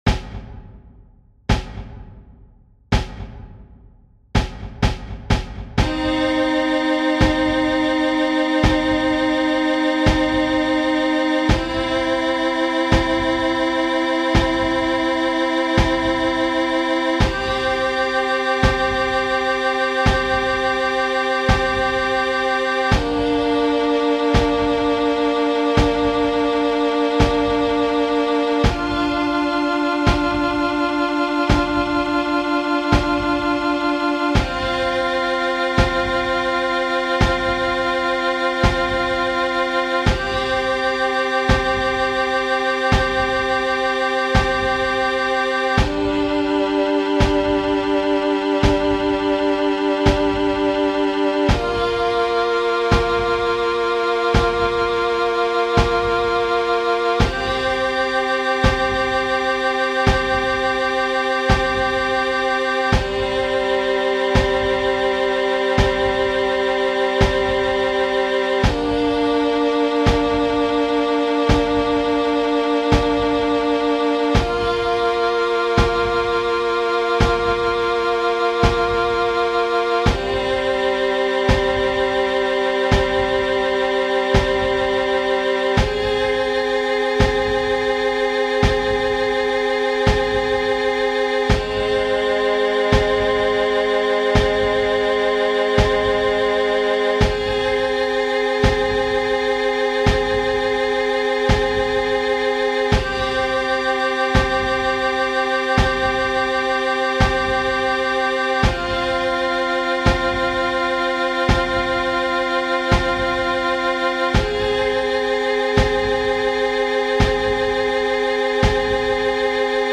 It has a lead-in, but it does NOT have a countdown.